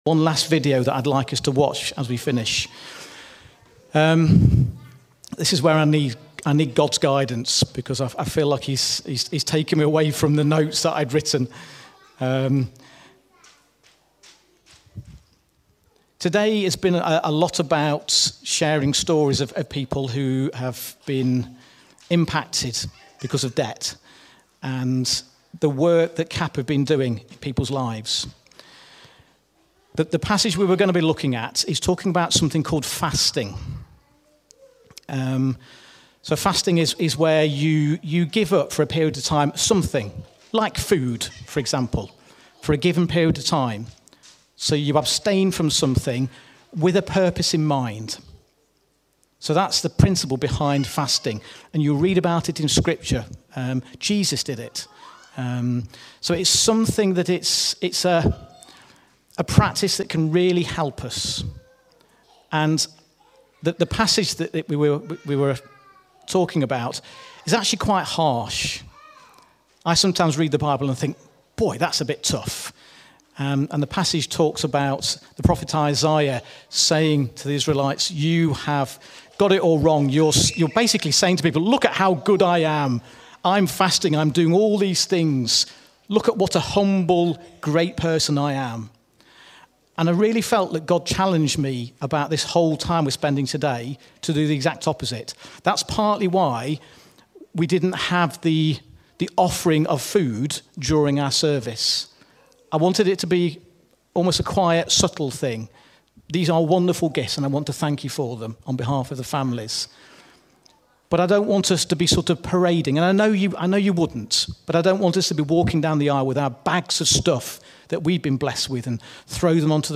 Reflection during our CAP Sunday celebration